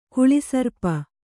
♪ kuḷi sarpa